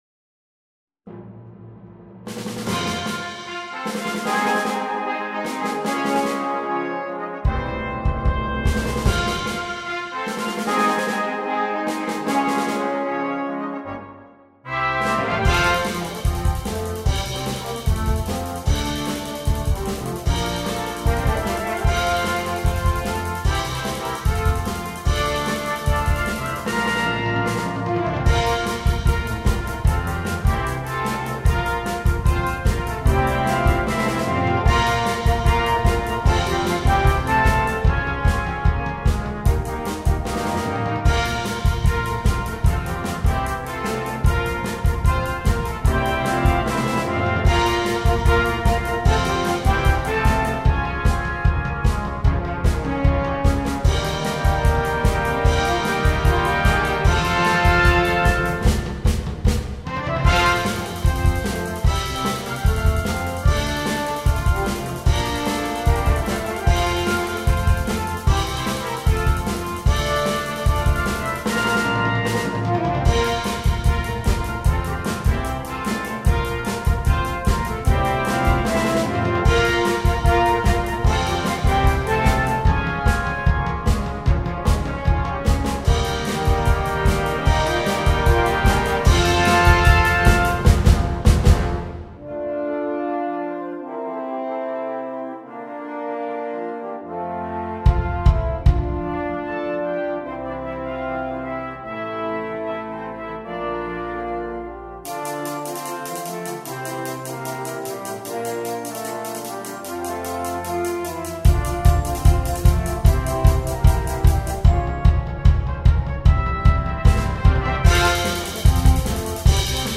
2. Brass Band
ohne Soloinstrument
Unterhaltung
leicht